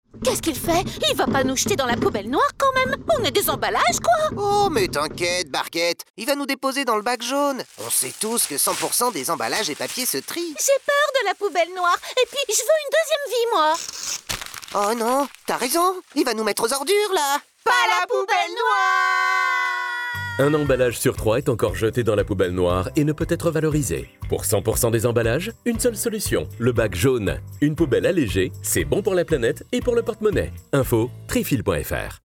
Spot radio
Spot radio campagne été 2025.mp3